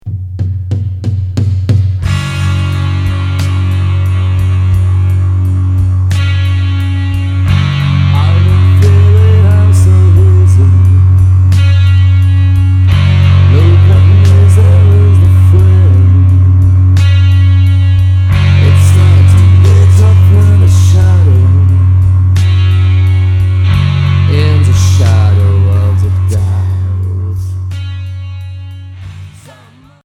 Garage